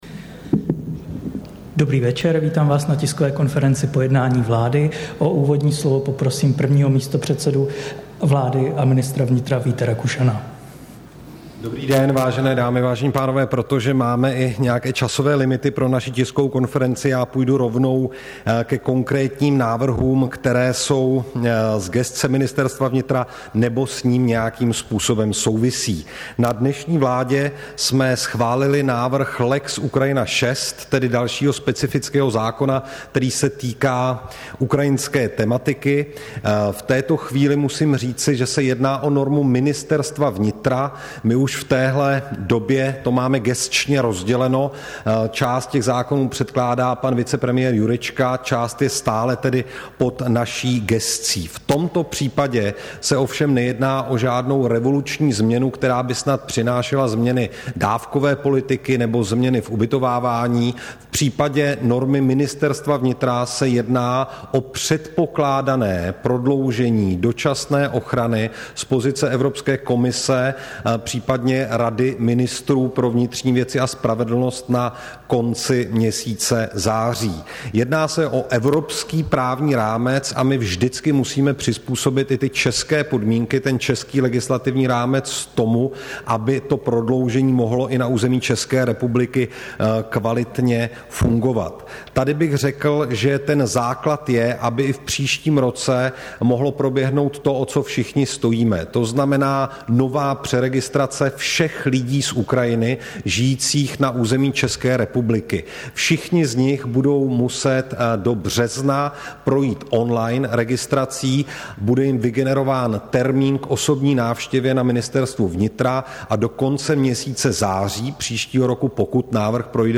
Tisková konference po jednání vlády, 13. září 2023